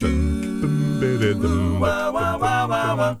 ACCAPELLA 9D.wav